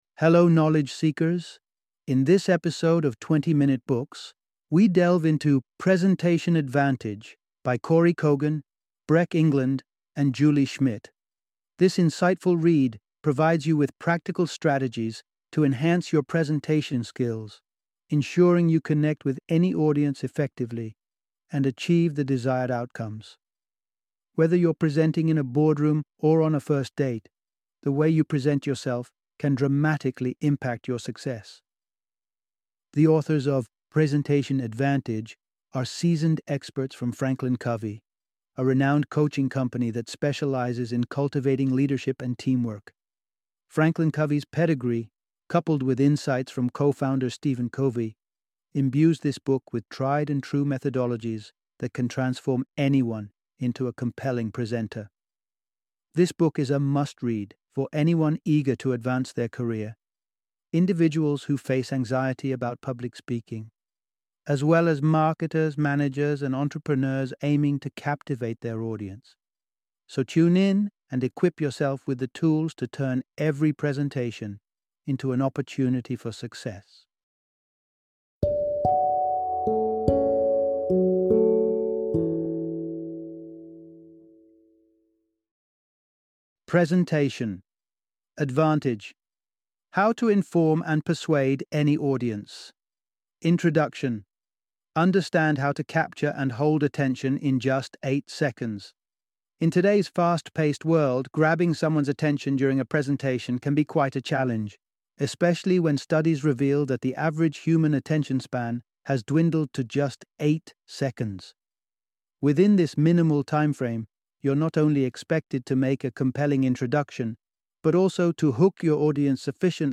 Presentation Advantage - Audiobook Summary